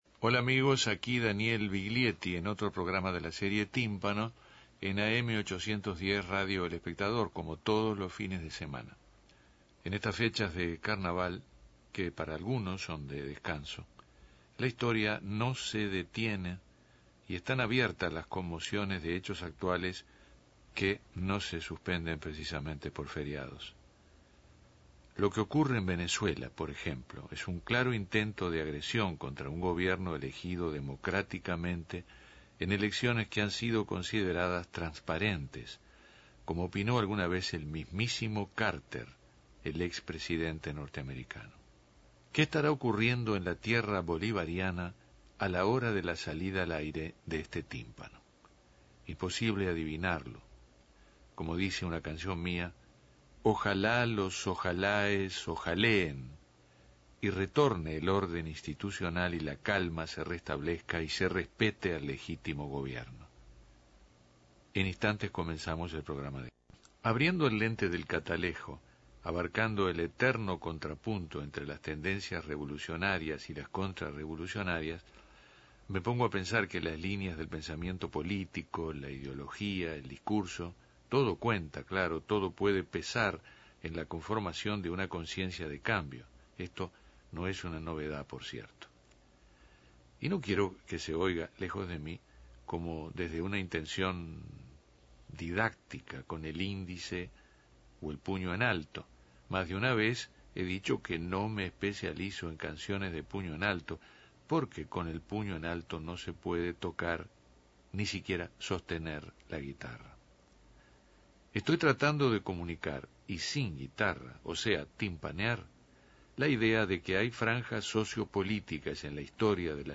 Escuche programa de Tímpano con poemas de Juan Gelman, pasajes de entrevistas y música